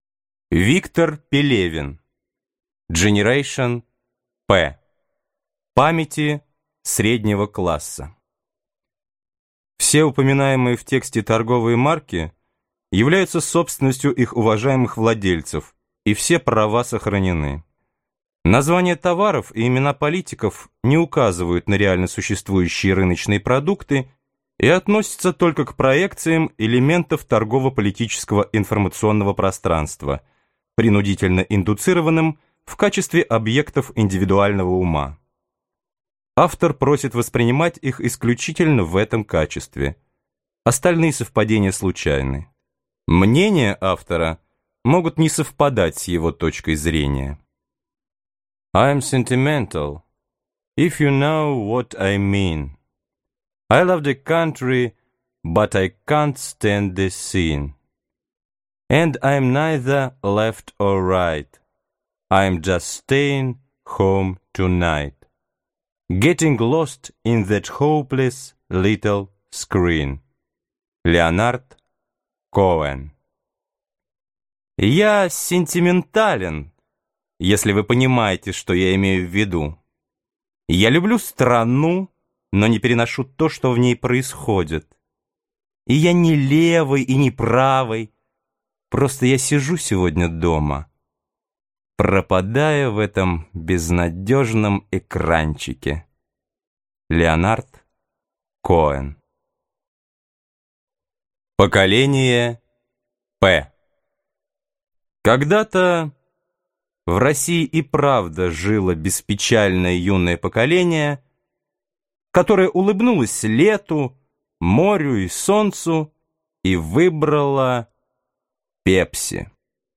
Аудиокнига Generation «П» (Поколение «Пи») | Библиотека аудиокниг